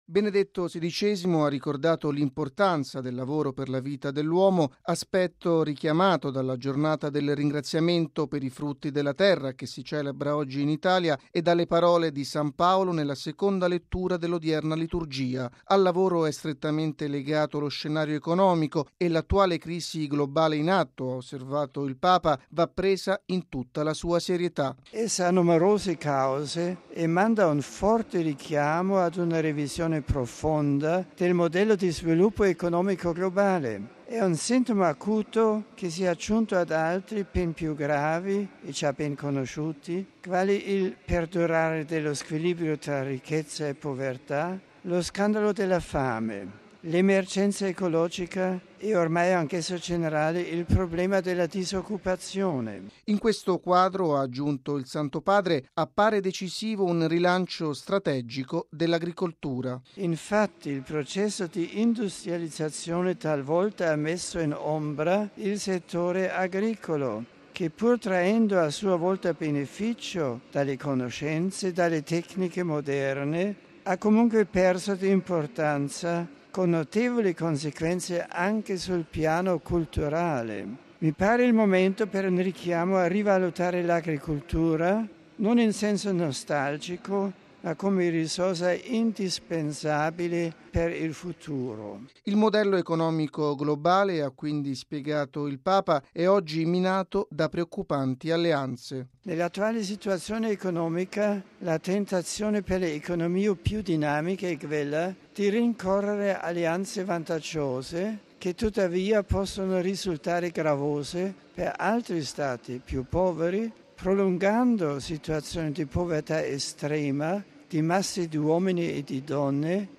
◊   All’Angelus Benedetto XVI si è soffermato sulla crisi economica globale. Tale situazione - ha detto il Santo Padre - è un "sintomo acuto" che si è aggiunto ad altri gravi squilibri.
Il servizio